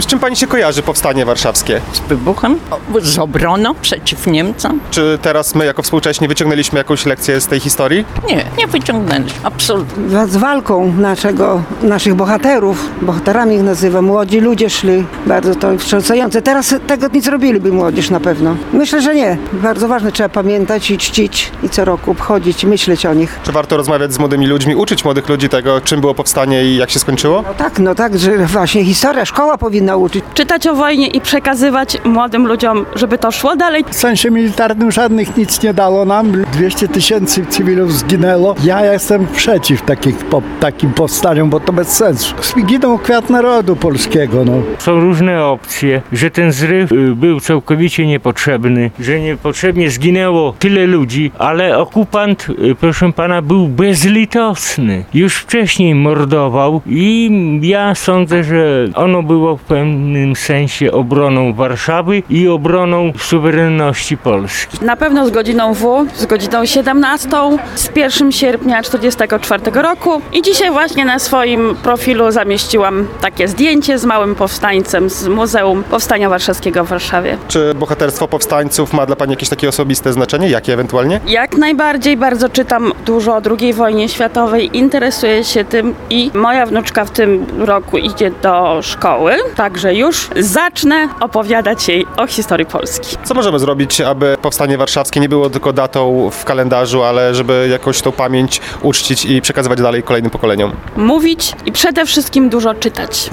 01-sonda-Powstanie-Warszawskie.mp3